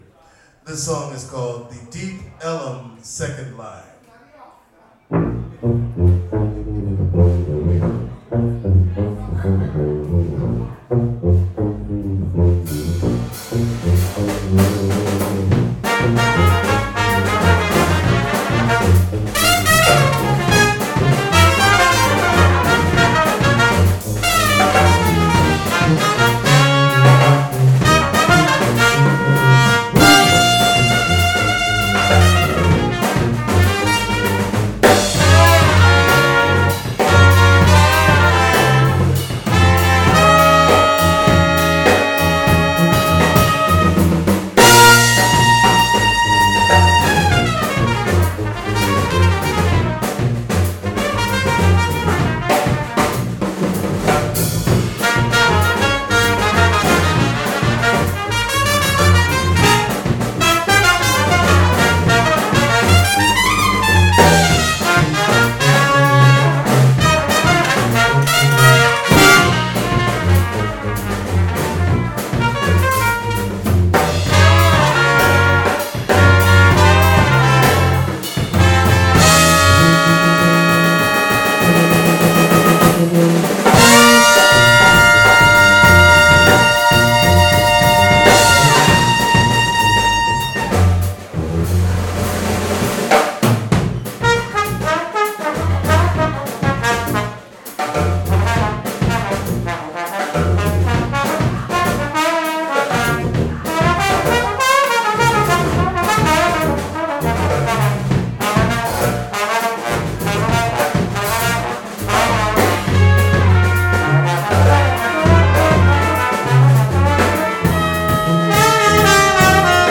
New Orleans brass band